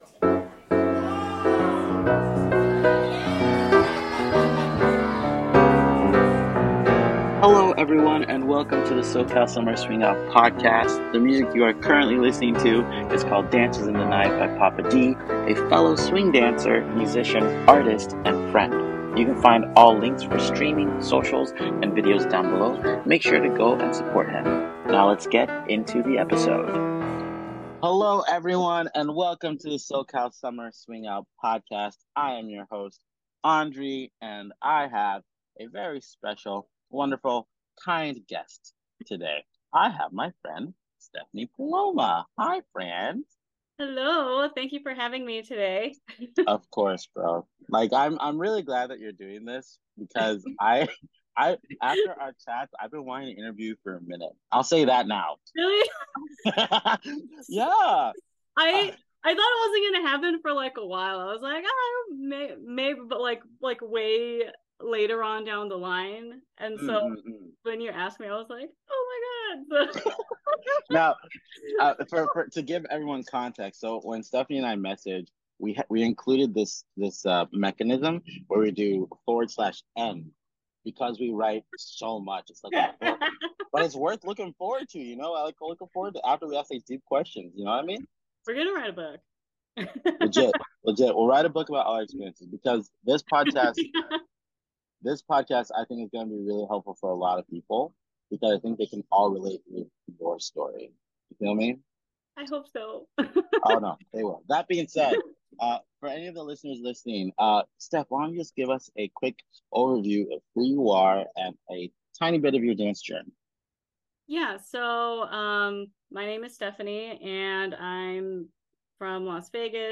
Here I interview all types of dancers on everything swing dance related!